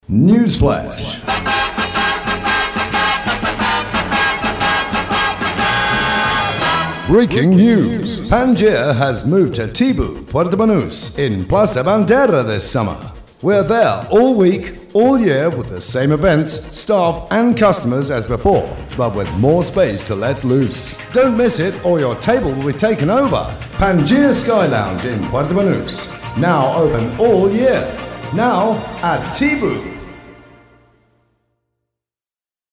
2007 - Voiceovers